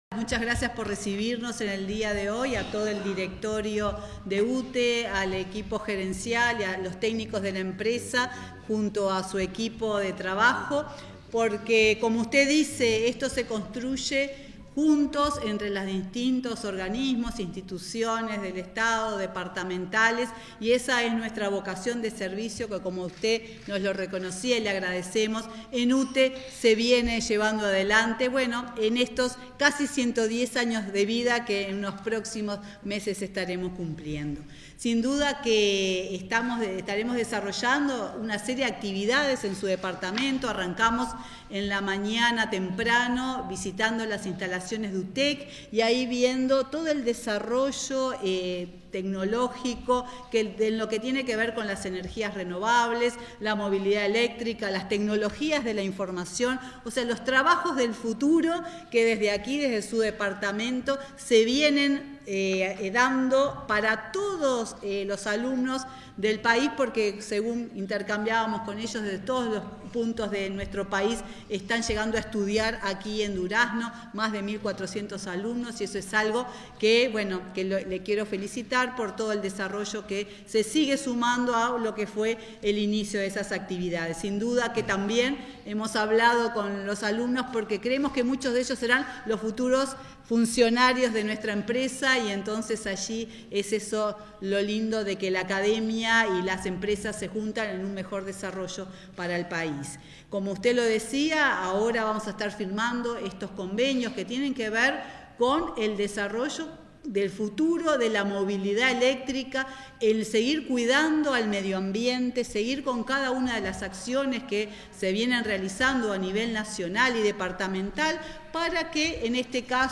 Palabras de la presidenta de UTE, Silvia Emaldi
Palabras de la presidenta de UTE, Silvia Emaldi 19/08/2022 Compartir Facebook X Copiar enlace WhatsApp LinkedIn Las autoridades de UTE firmaron acuerdos, este 19 de agosto, con la Intendencia de Durazno, a fin de ampliar la infraestructura de puntos de carga para la movilidad eléctrica y añadir disposiciones para el corrimiento de líneas de la red eléctrica. En la oportunidad, la presidenta de UTE destacó el convenio.